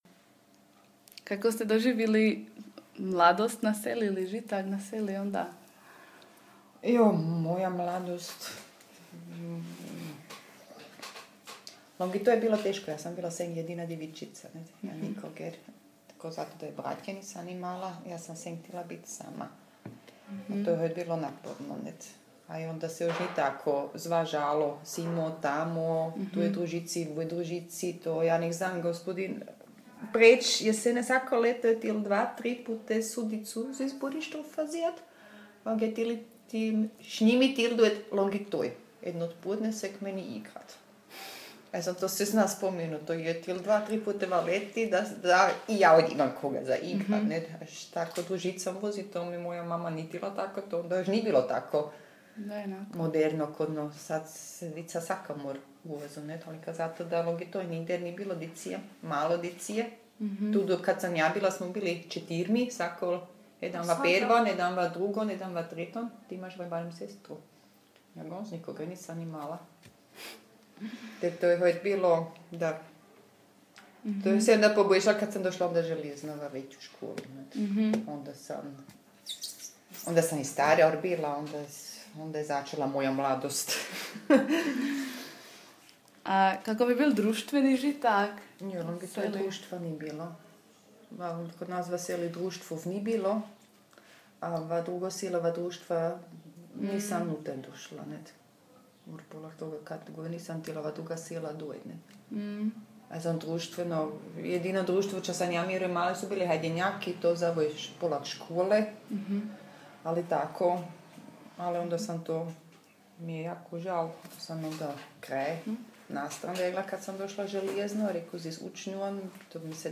Longitolj – Govor
24_Longitolj_govor.mp3